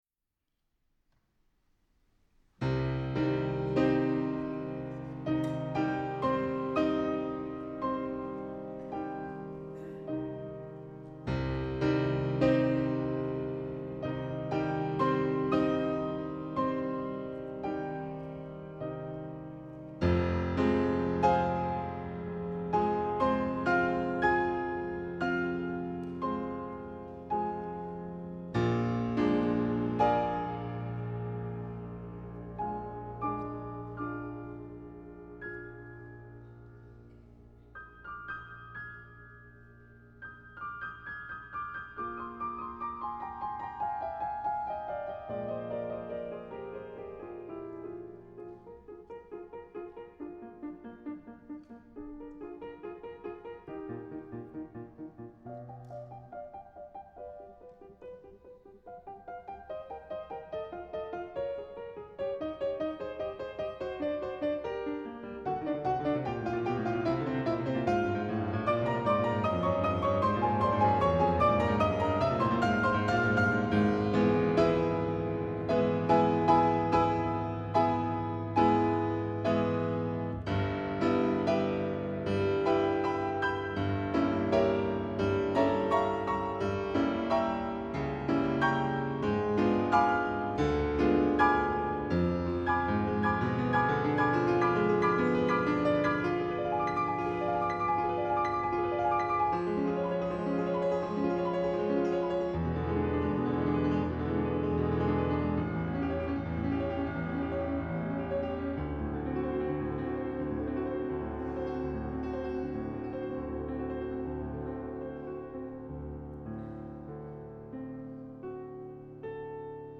Ludwig Van Beethoven: Fantasía en do menor para piano, coro y orquesta, Op. 80 "Fantasía Coral"
Concert season